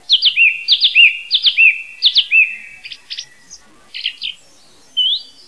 Taltrast häckar i barr- och blandskog, ibland i parker, i större delen av Europa och österut till Bajkalsjön. Den återvänder till Sverige från vinterkvarteren i v. och s.v. Europa i mars�april och framför då i gryning och skymning en omväxlande, kraftig sång, där varje fras upprepas några gånger i lugnt tempo.
Taltrast (Turdus philomelos).
taltrast.wav